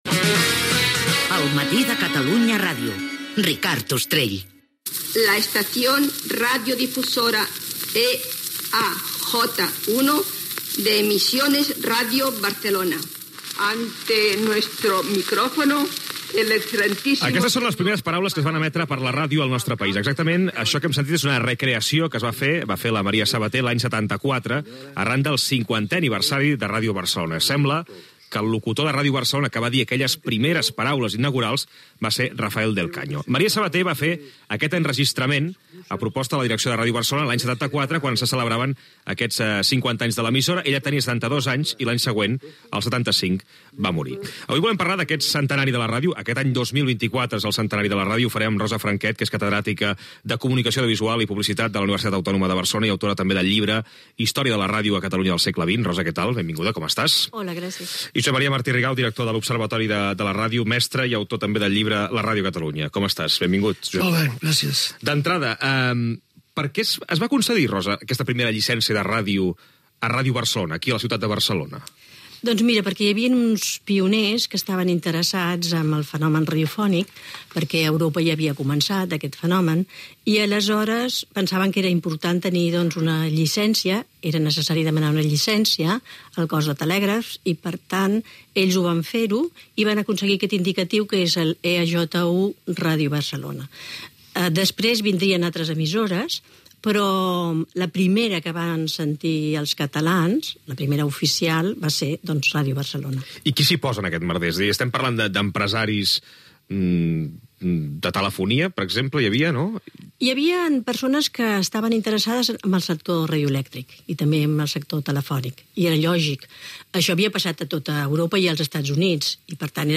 Indicatiu del programa i entrevista
Info-entreteniment